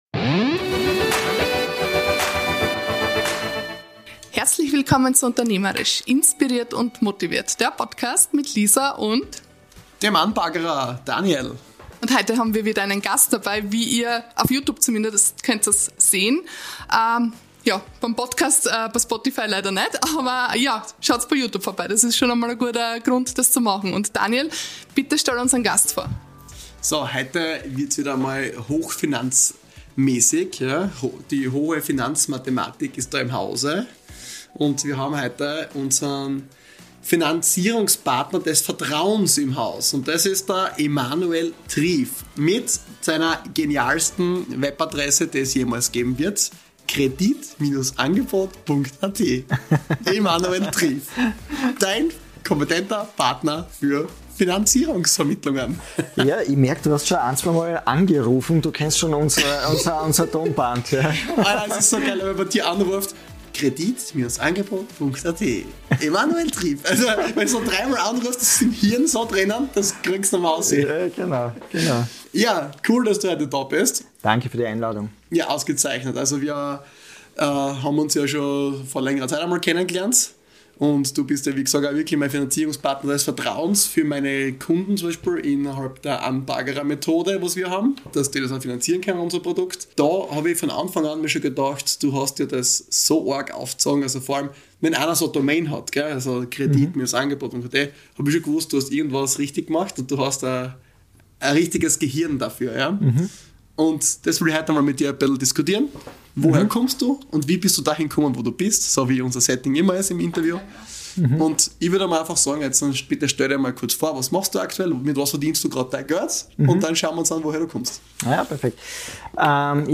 Episode 42: So ruinieren junge Menschen ihr finanzielles Glück | Interview